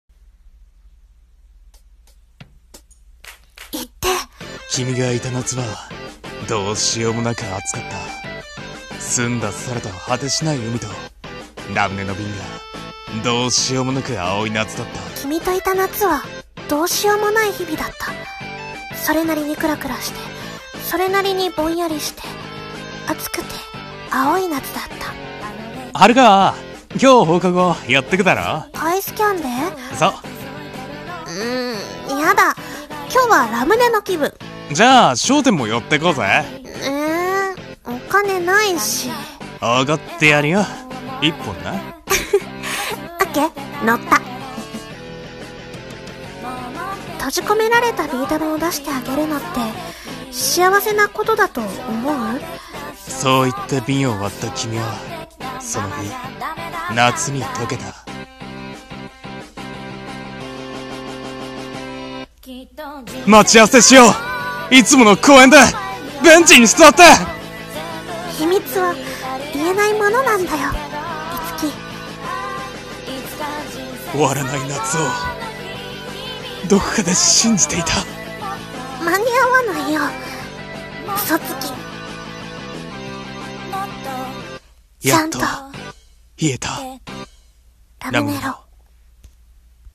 【映画予告風声劇】ラムネロ